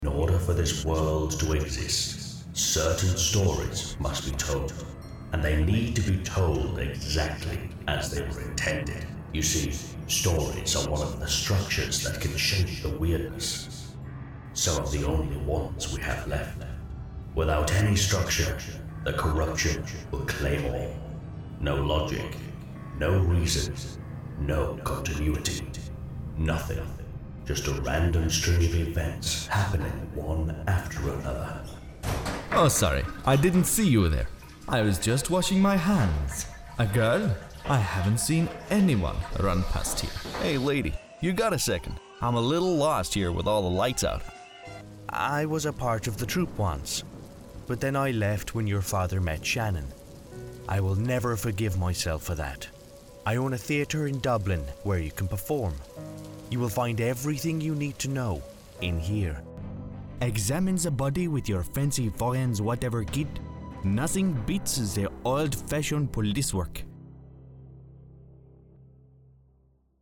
Male
British English (Native)
Approachable, Assured, Cheeky, Confident, Conversational, Corporate, Energetic, Reassuring, Sarcastic, Smooth, Warm
Geordie, RP, British, American
Microphone: Neumann TLM103 / Senheisser MKH-416 / Rode NT2